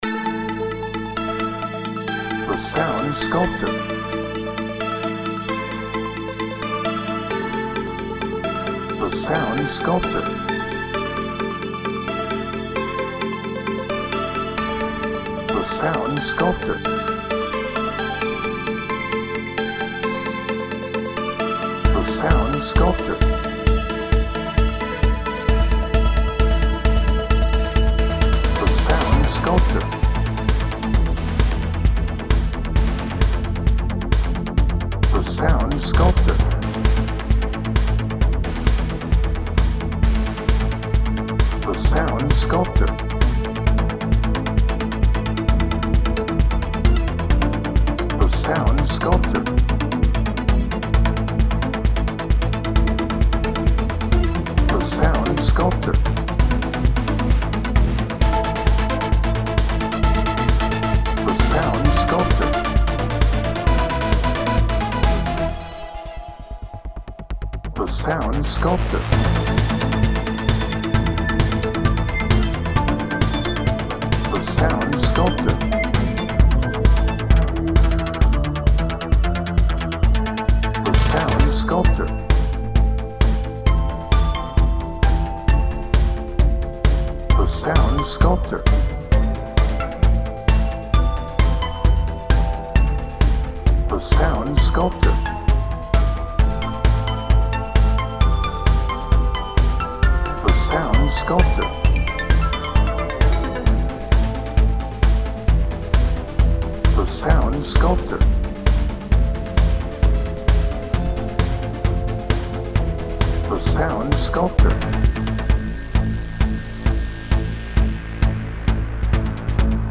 Bright
Electronic
Positive
Tense